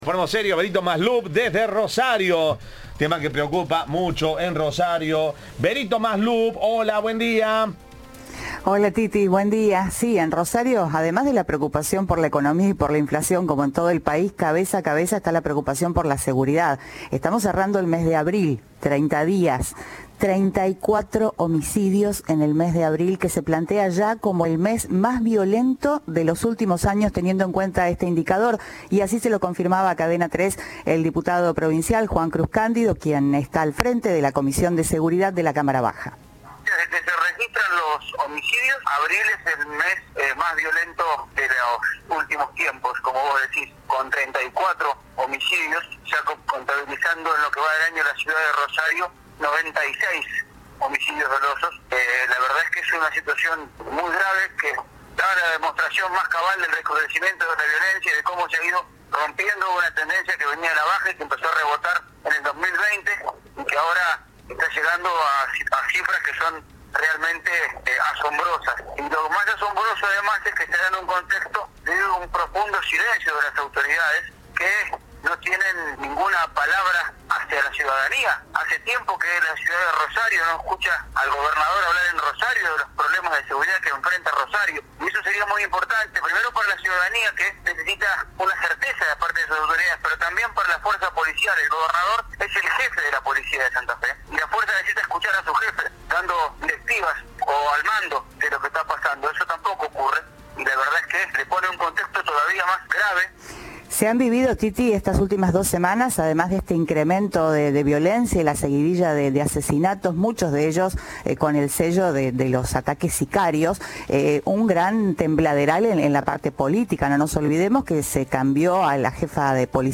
El diputado provincial Juan Cruz Cándido habló con Cadena 3 y definió la situación como “muy grave” y habló de un recrudecimiento de la violencia urbana, que aseguró aumentó en 2020 cuando antes los índices de homicidios “venían en baja”.
Informe